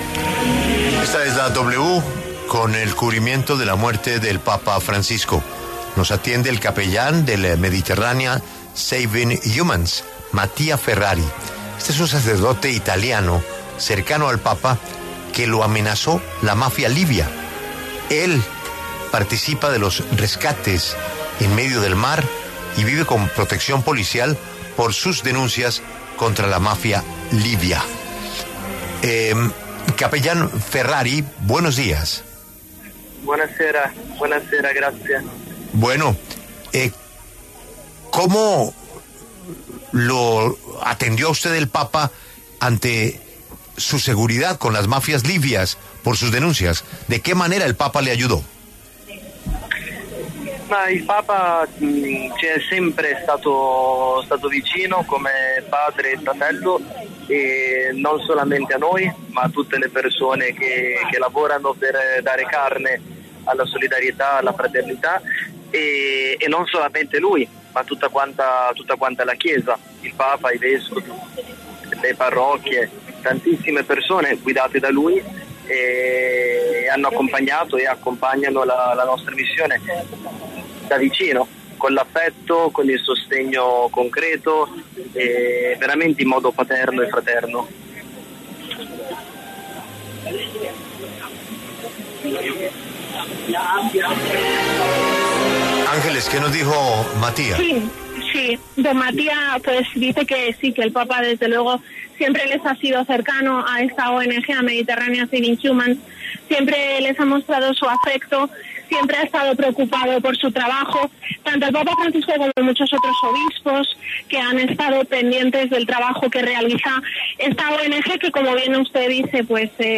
pasó por los micrófonos de La W